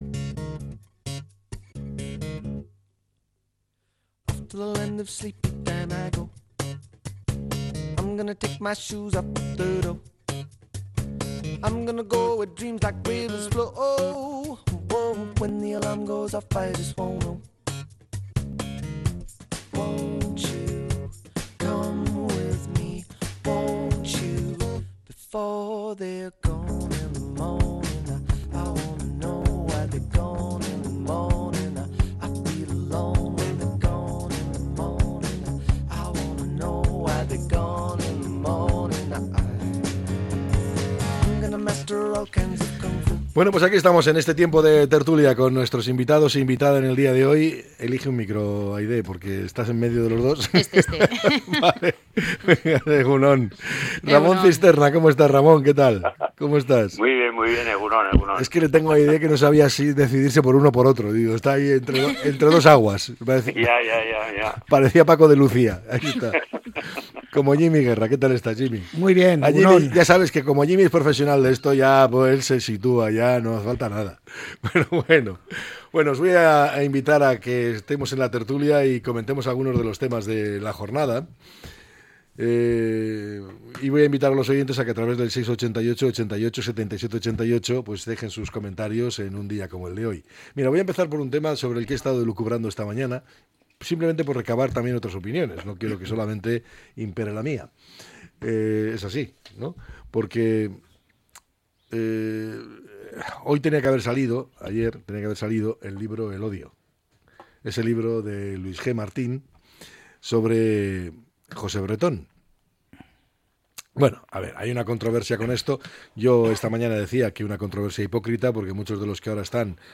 La tertulia 27-03-25.